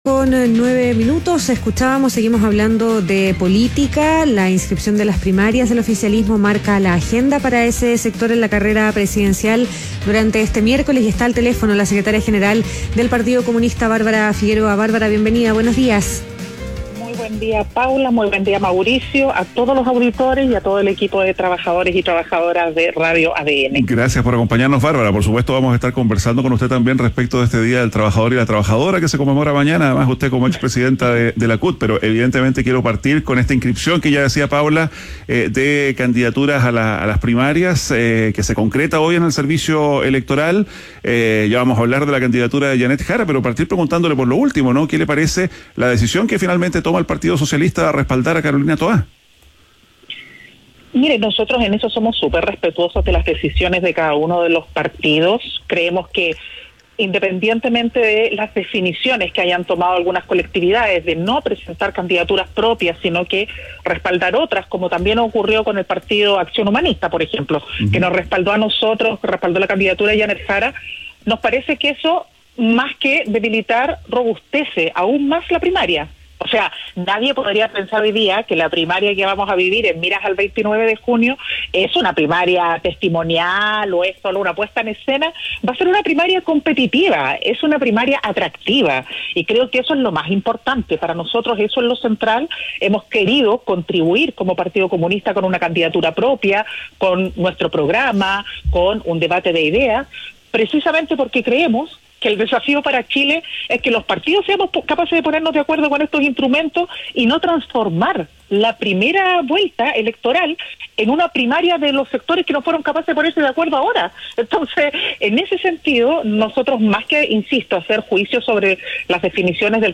ADN Hoy - Entrevista a Bárbara Figueroa, secretaria general del Partido Comunista